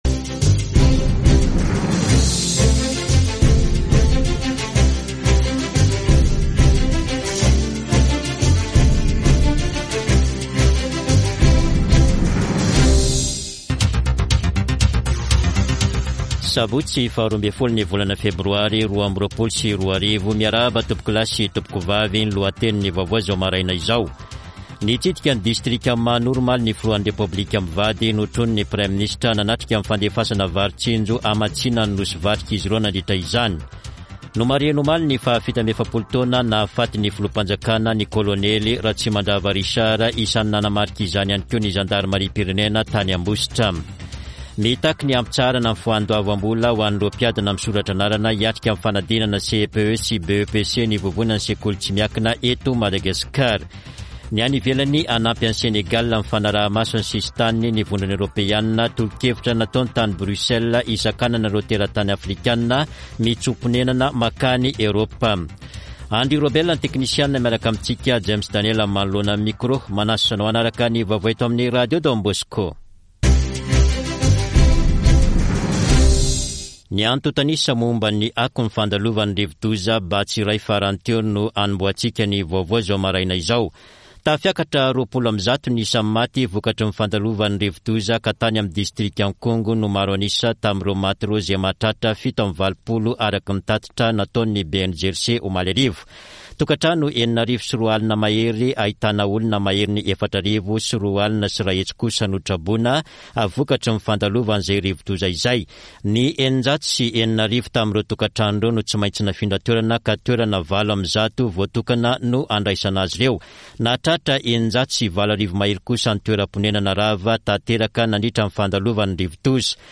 [Vaovao maraina] Sabotsy 12 febroary 2022